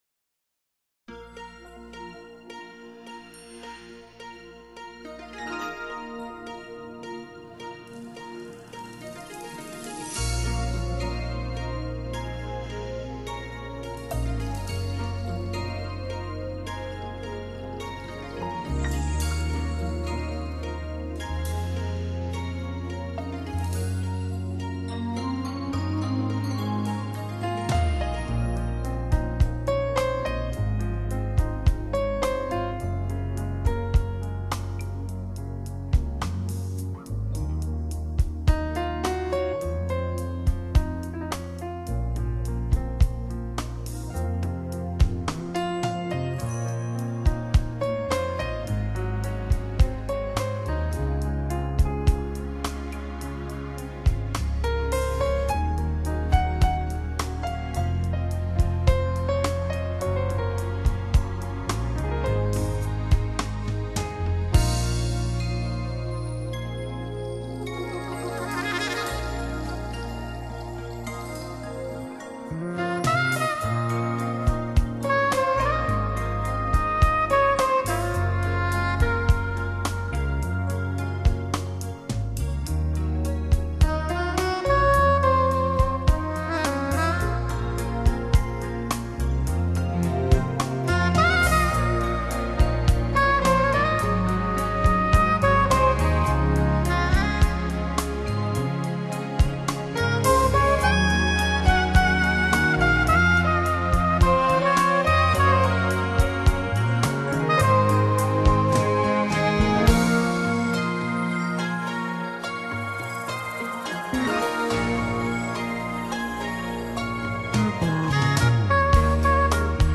Genre: Smooth Jazz, New Age